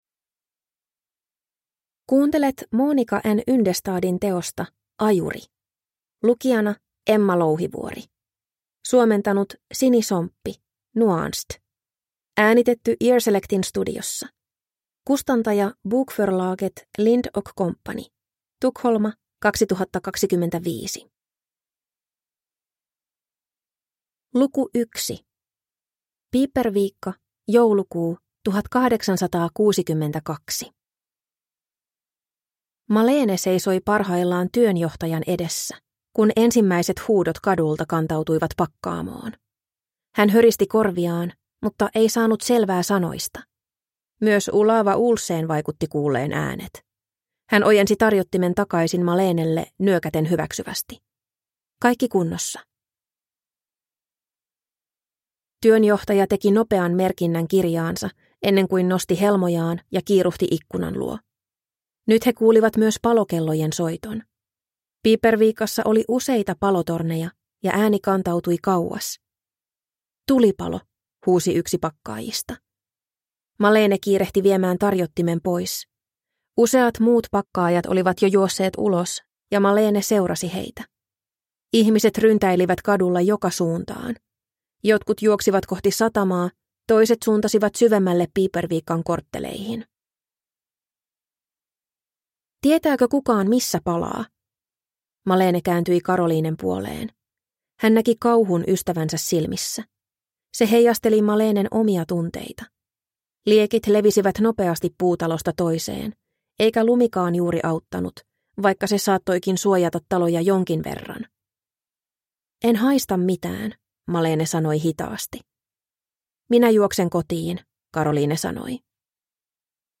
Ajuri – Ljudbok